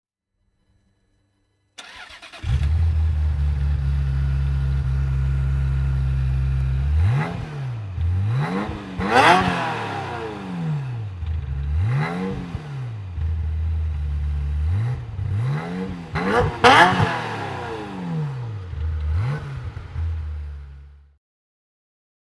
Ferrari F355 F1 Spider (1999) - Starten und Leerlauf